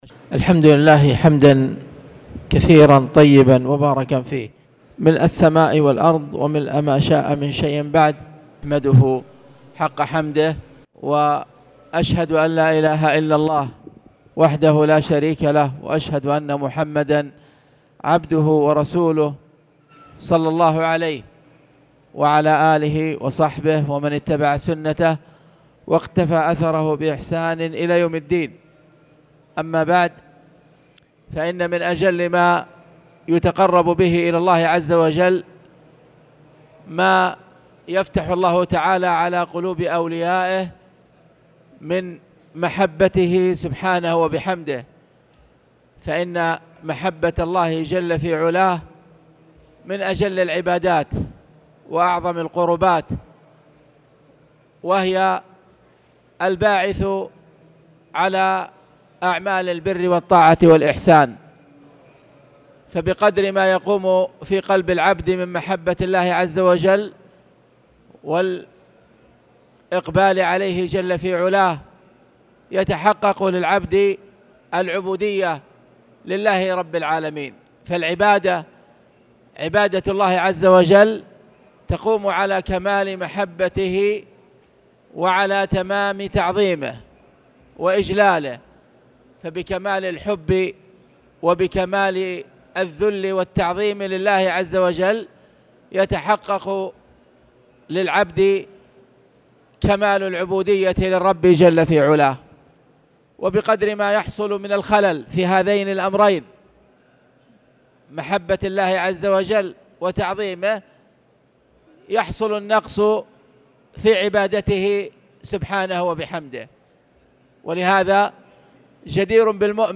تاريخ النشر ٢ شعبان ١٤٣٨ هـ المكان: المسجد الحرام الشيخ